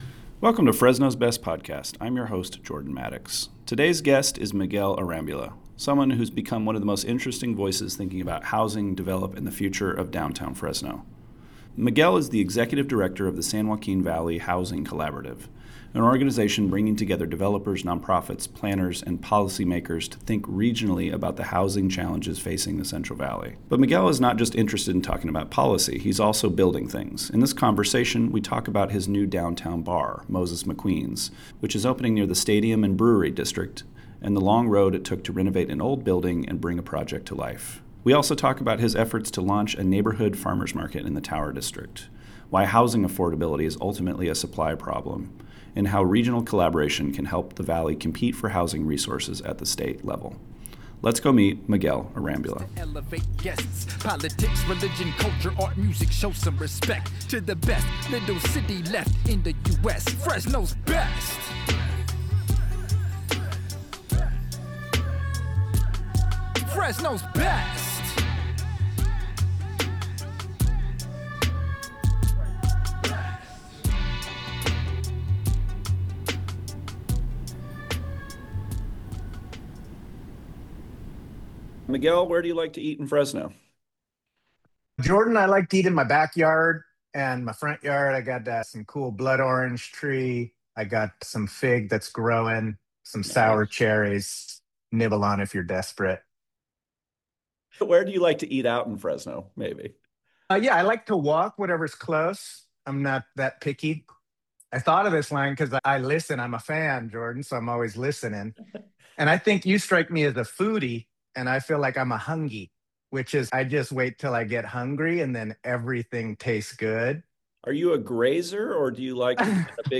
for a wide-ranging conversation about housing, entrepreneurship, and the future of downtown Fresno.